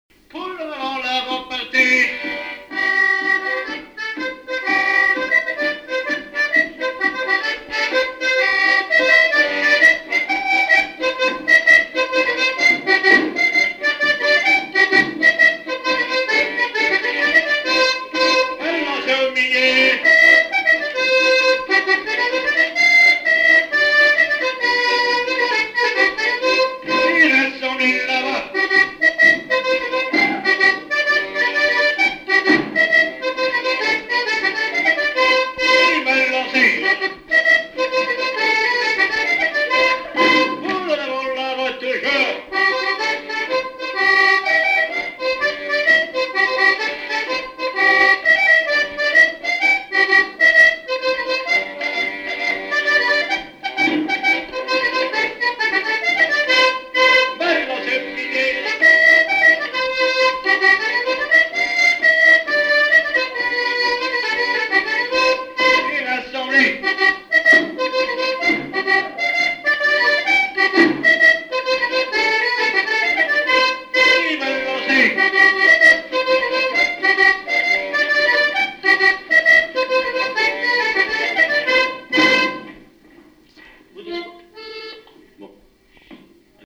danse : quadrille : poule
Répertoire du violoneux
Pièce musicale inédite